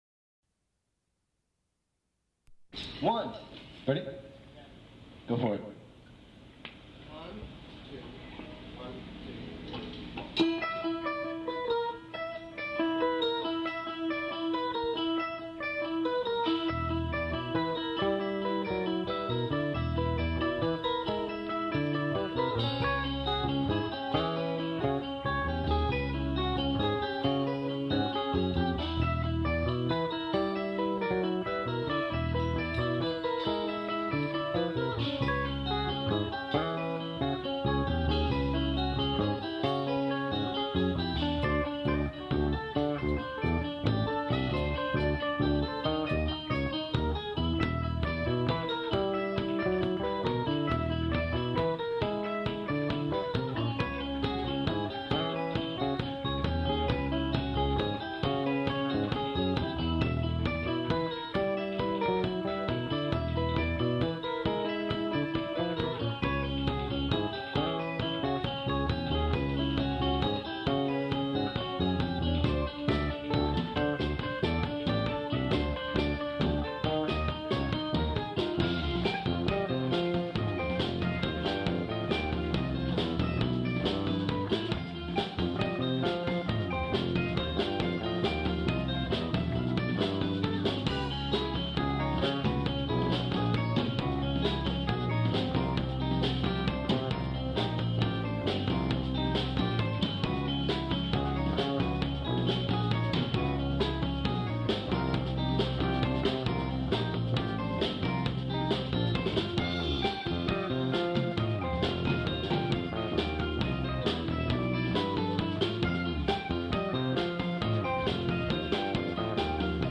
Electric Bassist in Los Angeles For Approximately Four Years
Van Gogh's Ear rehearsal before gig at Madam Wongs West, LA playing "Hands Head Heart"